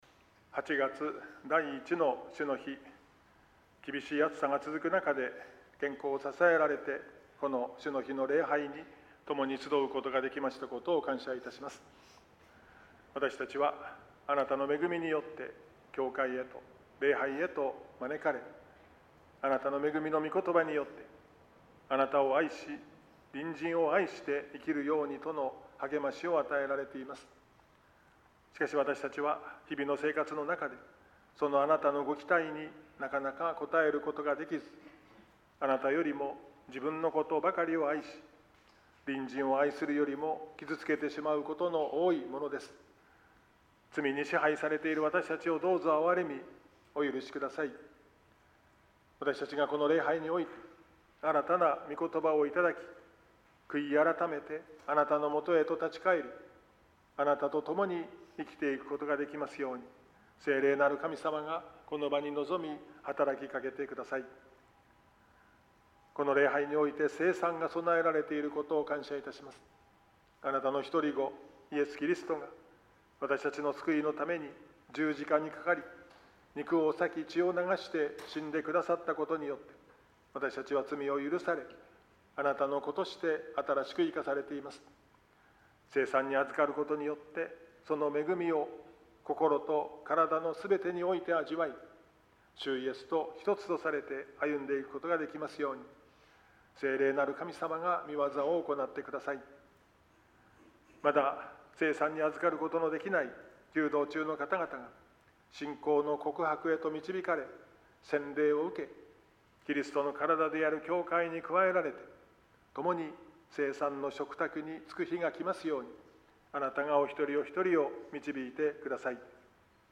83主日礼拝音源.mp3